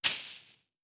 MatchExtinguish.wav